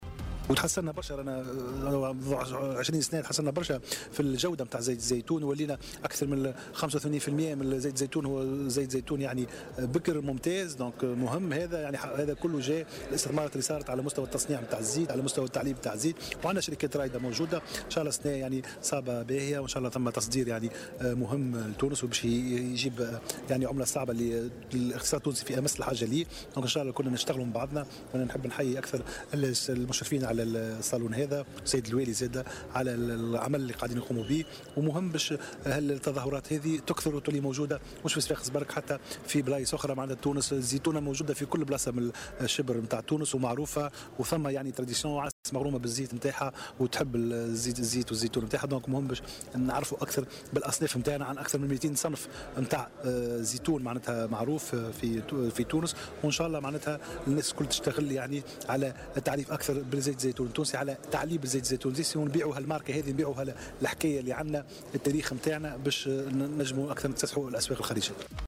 وأشار في تصريح اليوم لمراسل "الجوهرة أف أم" على هامش أشغال منتدى علمي حول زيت الزيتون ضمن الدورة الثانية لمهرجان الزيتونة بصفاقس، إلى أهمية التعريف بأصناف الزيتون في تونس، والقيام بحملات ترويجية لاقتحام الأسواق الخارجية.